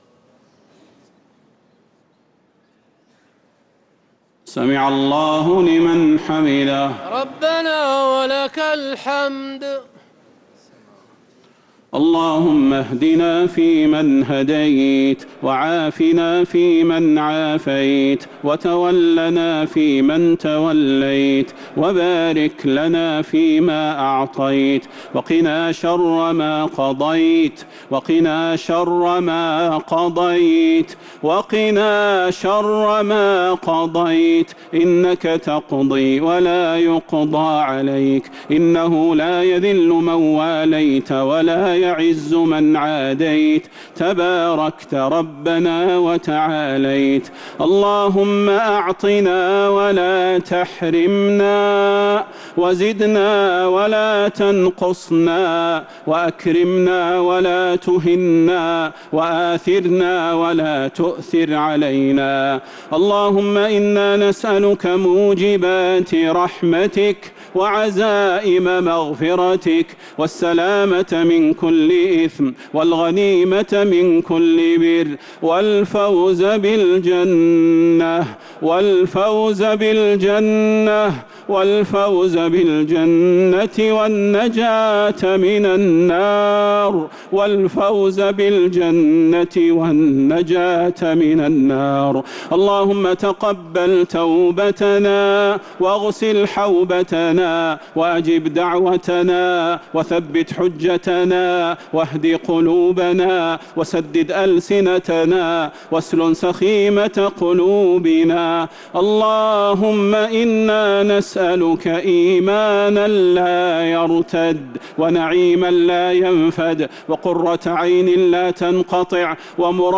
دعاء القنوت ليلة 18 رمضان 1447هـ | Dua 18th night Ramadan 1447H > تراويح الحرم النبوي عام 1447 🕌 > التراويح - تلاوات الحرمين